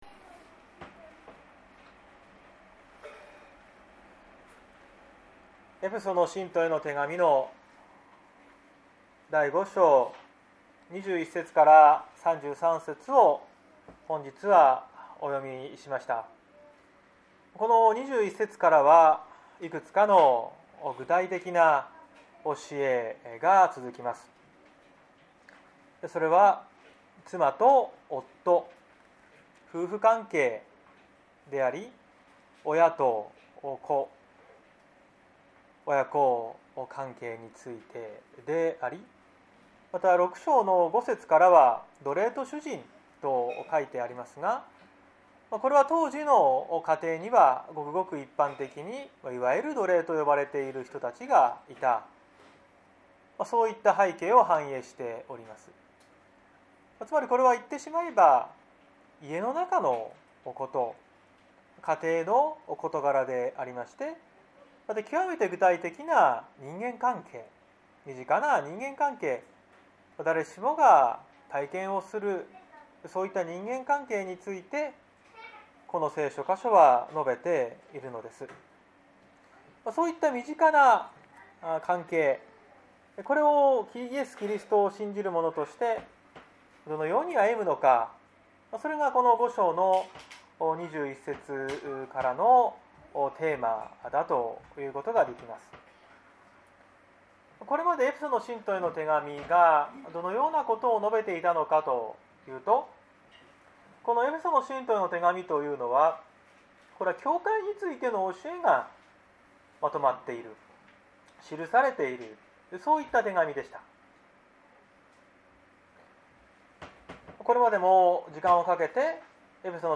2021年04月11日朝の礼拝「「結婚と教会―偉大な愛の神秘―」」綱島教会
綱島教会。説教アーカイブ。